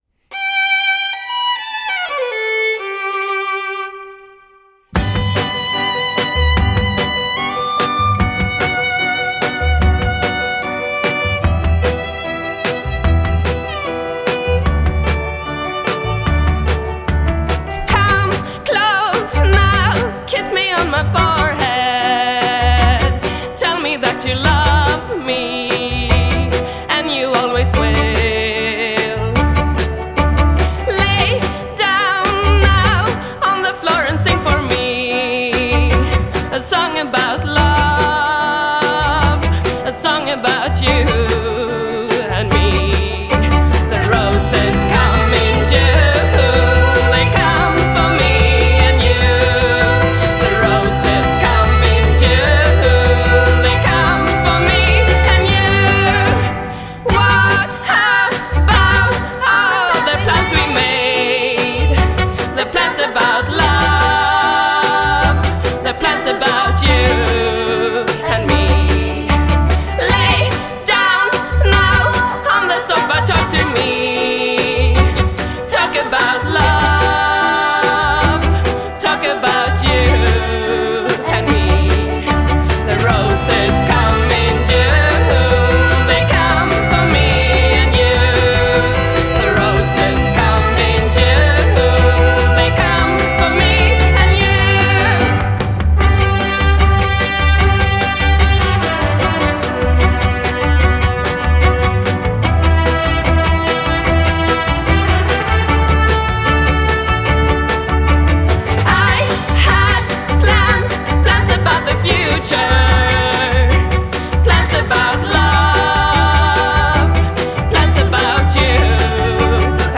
Swedish indie act
alt-country